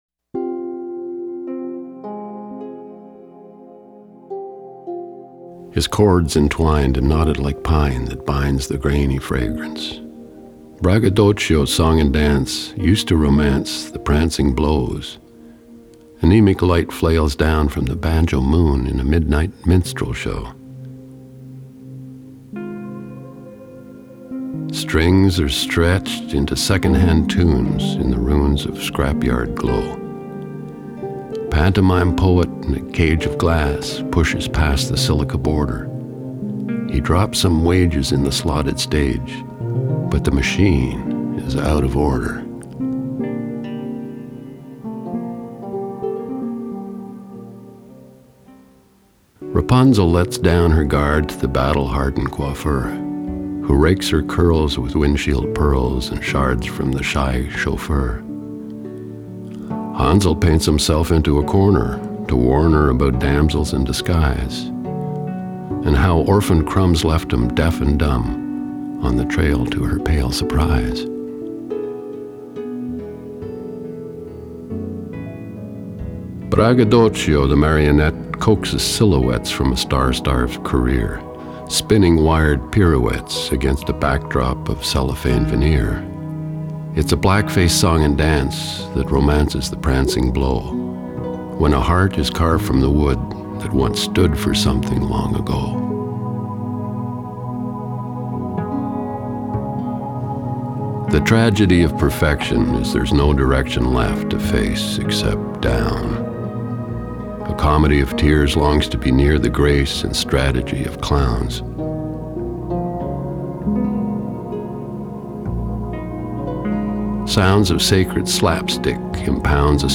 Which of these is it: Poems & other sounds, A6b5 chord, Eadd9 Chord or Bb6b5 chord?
Poems & other sounds